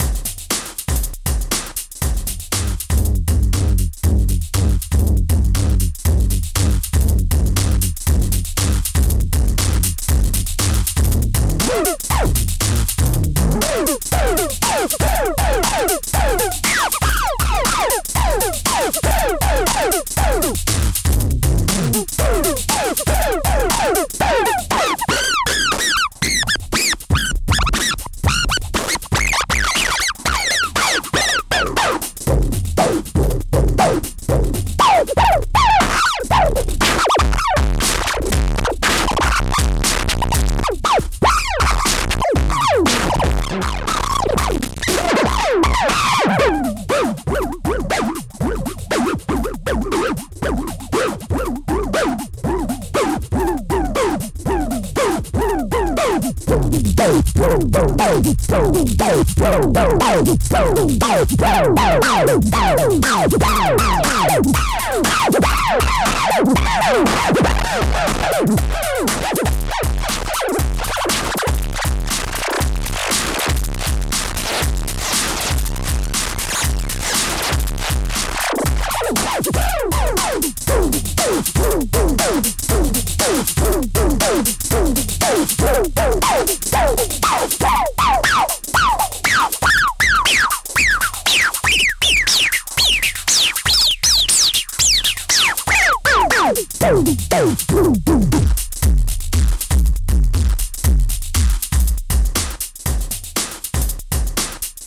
My latest Shermanik Filterbank v4.0 Bitwig Studio preset.